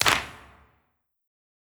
CLAP_BREAKBREAD.wav